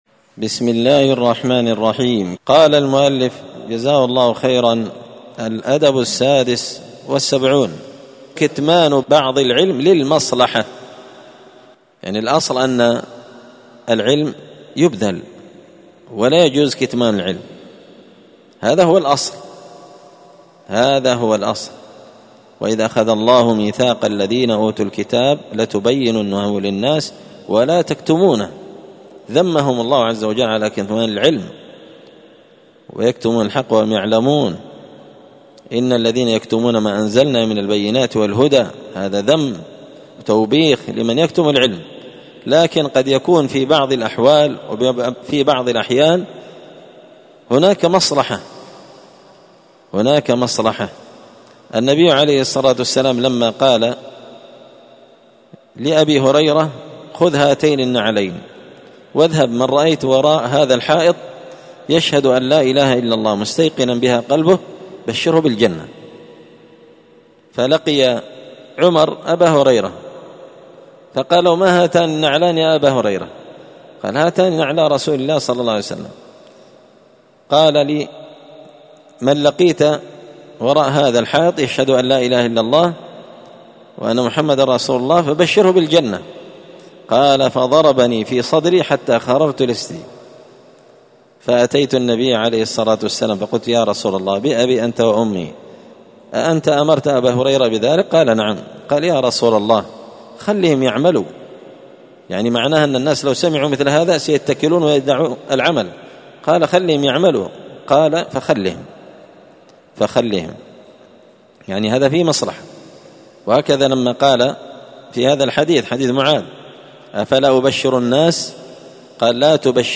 تعليق وتدريس الشيخ الفاضل:
الأربعاء 29 محرم 1445 هــــ | الدروس، النبذ في آداب طالب العلم، دروس الآداب | شارك بتعليقك | 66 المشاهدات